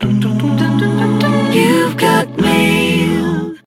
15_Youve_Got_Mail.ogg